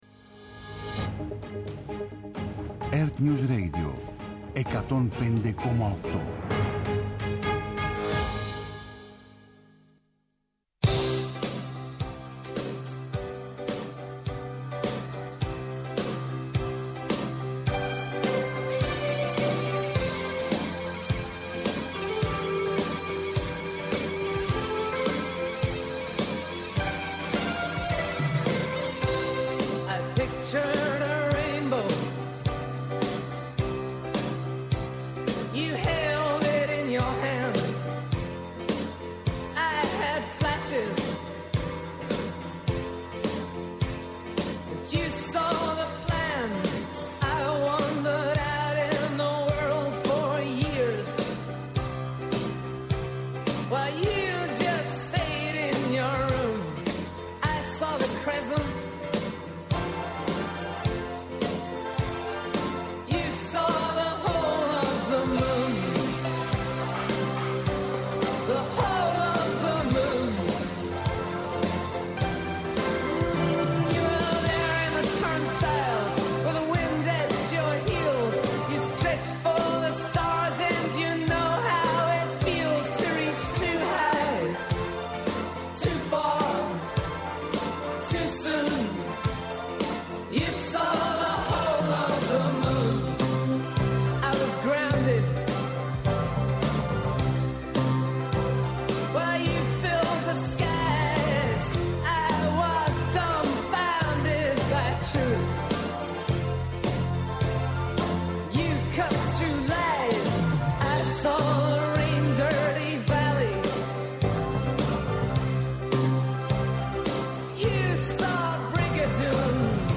Από το 11ο Οικονομικό Φόρουμ των Δελφών